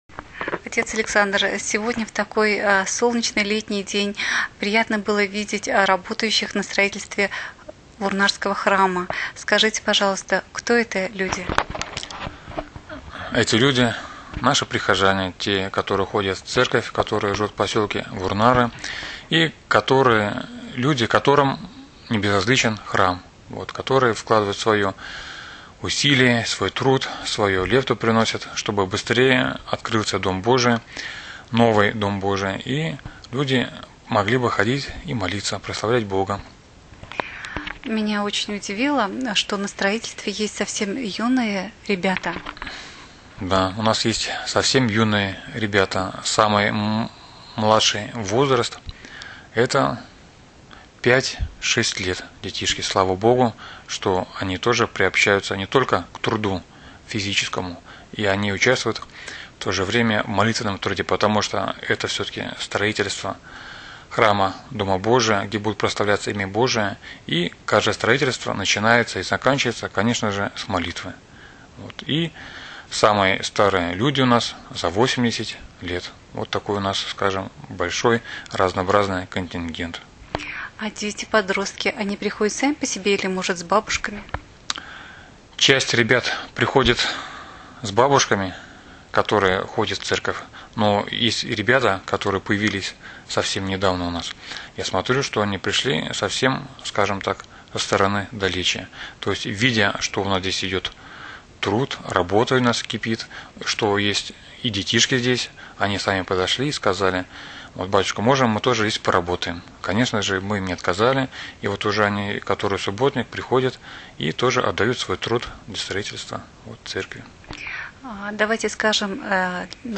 аудиозапись интервью):